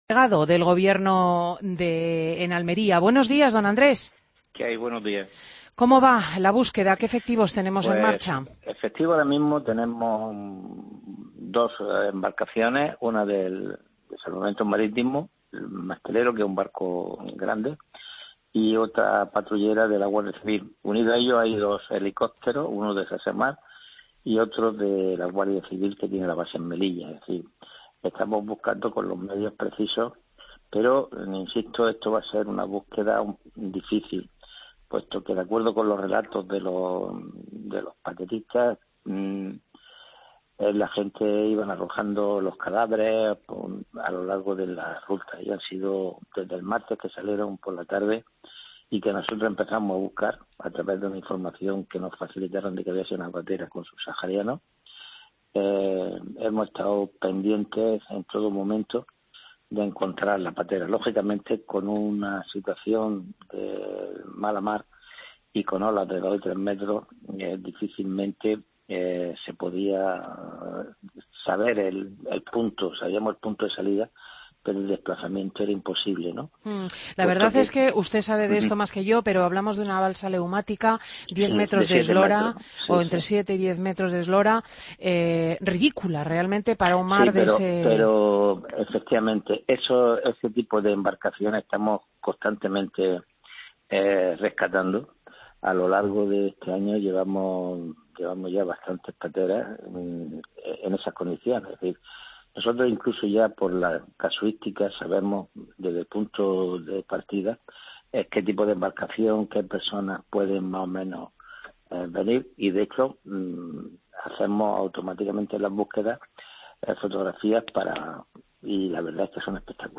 Entrevista Subdelegado Gobierno Almería en Fin de Semana COPE